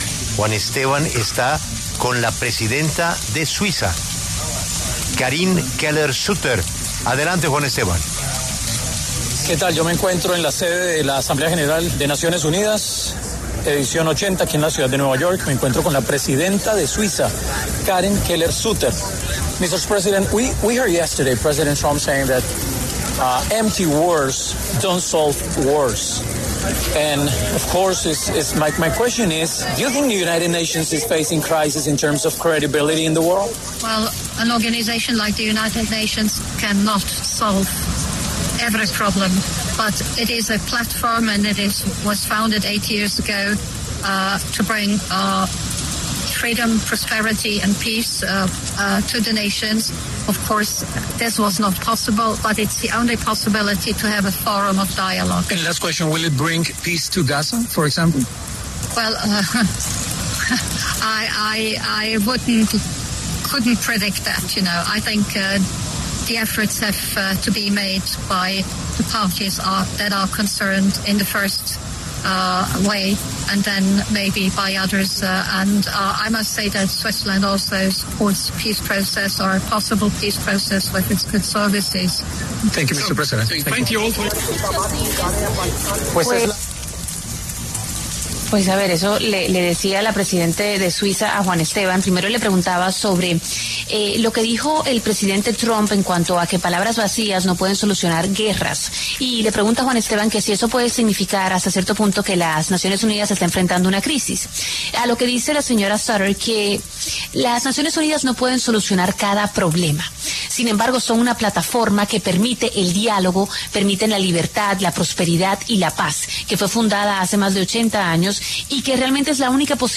Karin Keller-Sutter, presidenta de la Confederación Suiza, pasó por los micrófonos de La W para hablar sobre el discurso de Donald Trump en medio de la Asamblea General de la ONU.